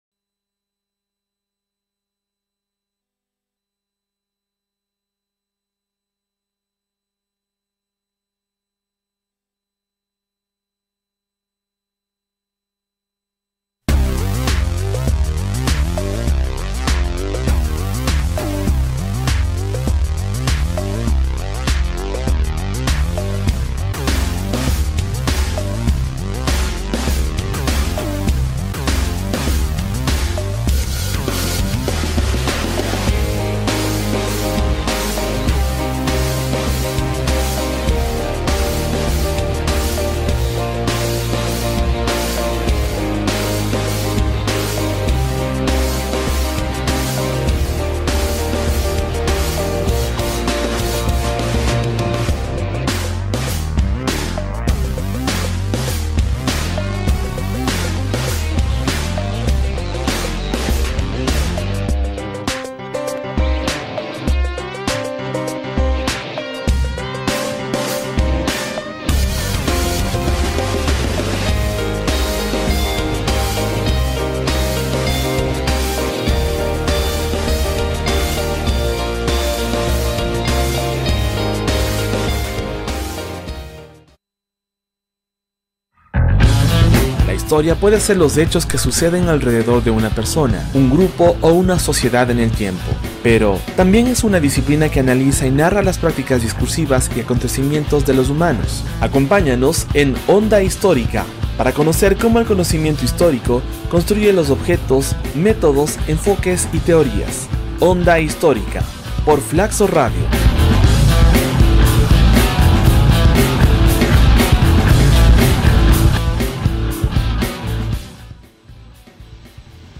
En la entrevista se conoce la identificación y reconstrucción de los diversos regímenes de ejercicio de poder que se sucedieron en Occidente en los últimos cuatro siglos, con el objeto de promover una comprensión genealógica de lo político. Se habla de la presencia de Dios, de los mediadores y mucho más.